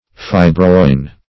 Meaning of fibroin. fibroin synonyms, pronunciation, spelling and more from Free Dictionary.
Search Result for " fibroin" : The Collaborative International Dictionary of English v.0.48: fibroin \fi"bro*in\ (f[imac]"br[-o]*[i^]n or f[i^]b"r[-o]*[i^]n), n. [L. fibra a fiber.]